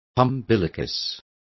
Complete with pronunciation of the translation of umbilicus.